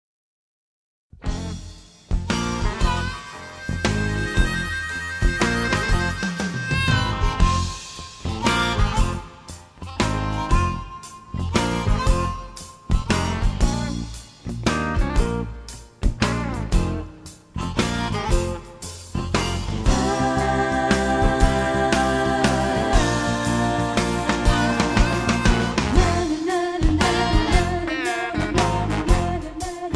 rock and roll, country rock